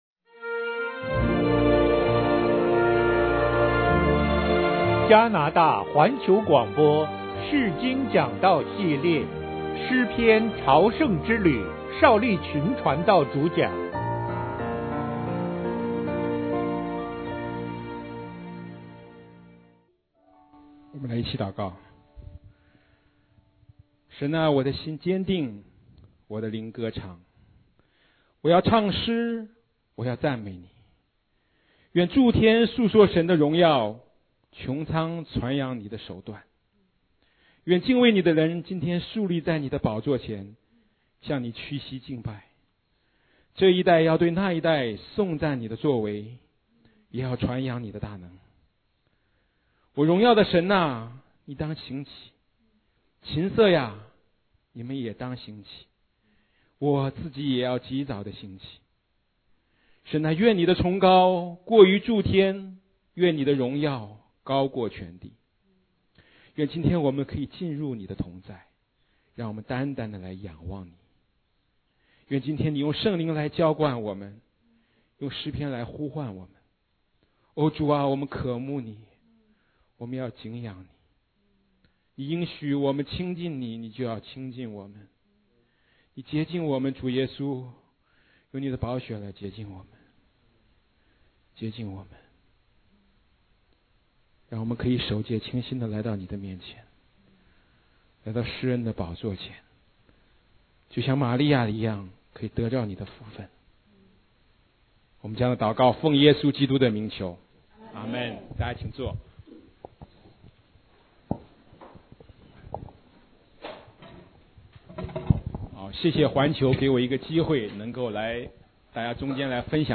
釋經講道系列 – 詩篇 – 加拿大環球廣播